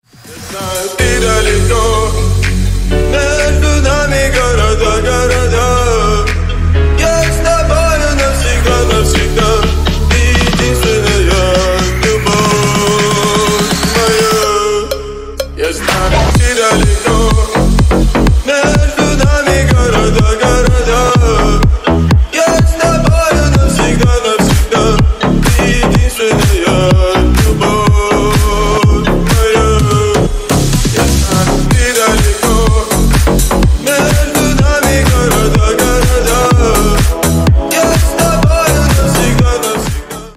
Поп Музыка
клубные # кавер